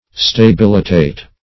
Search Result for " stabilitate" : The Collaborative International Dictionary of English v.0.48: Stabilitate \Sta*bil"i*tate\ (-t[=a]t), v. t. [LL. stabilitatus, p. p. of stabilitare to make stable.]